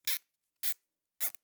Zip Ties Secure 3 Sound
household